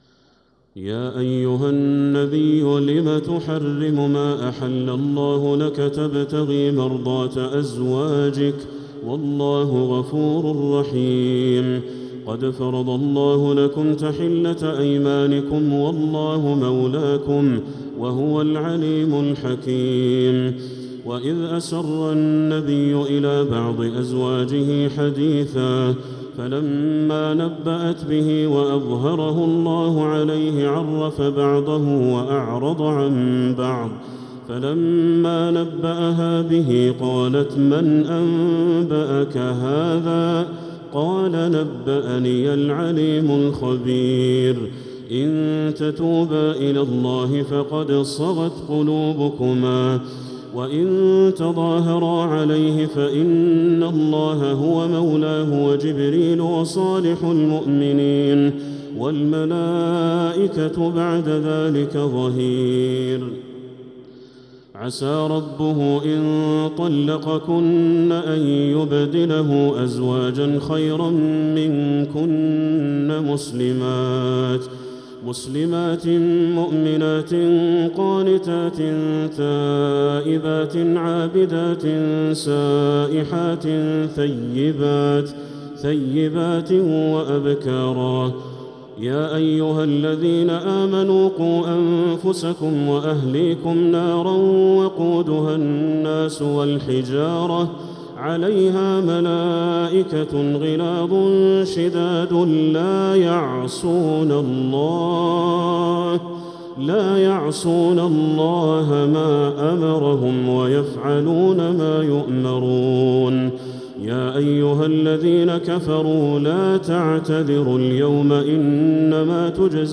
سورة التحريم | مصحف تراويح الحرم المكي عام 1446هـ > مصحف تراويح الحرم المكي عام 1446هـ > المصحف - تلاوات الحرمين